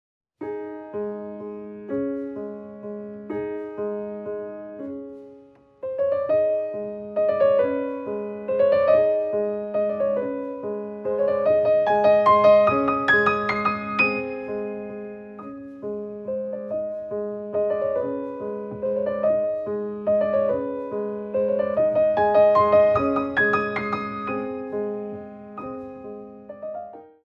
Ballet class music for Intermedite Level
Beautifully recorded on a Steinway and Sons Grand Piano